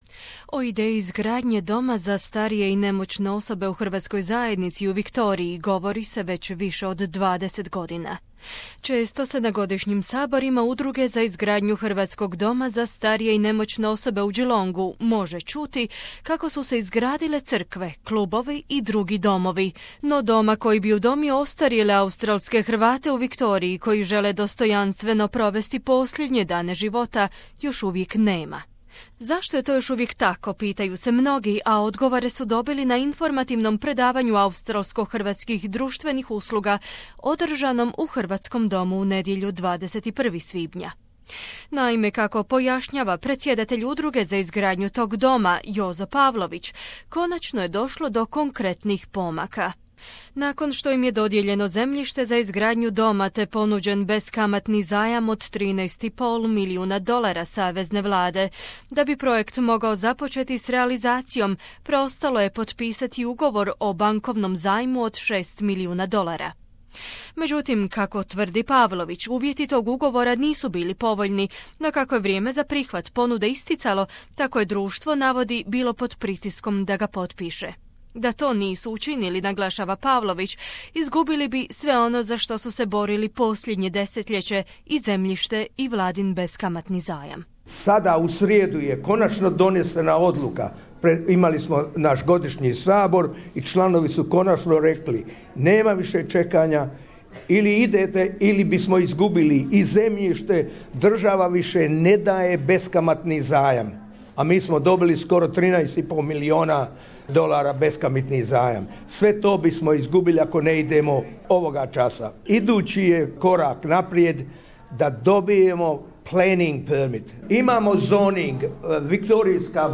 In front of more than 100 retirees he spoke about the project stressing it's long battle towards realization and the latest news.